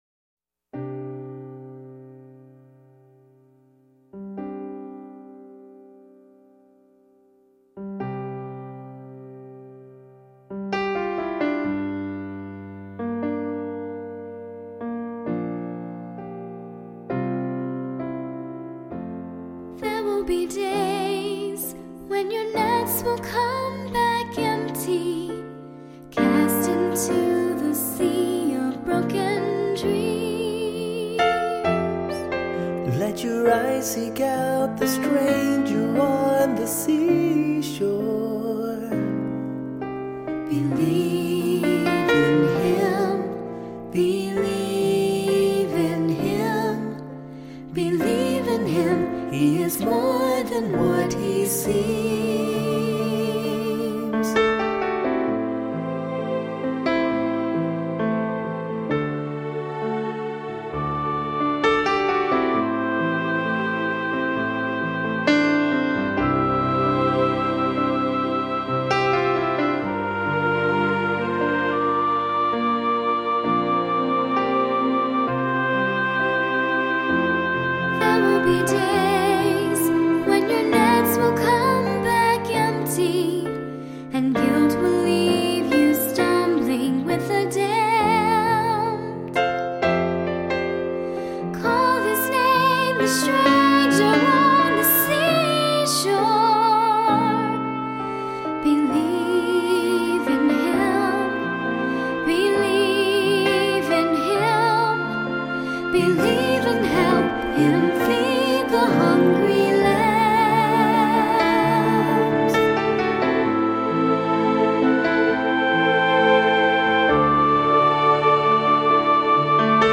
Voicing: SATB, cantor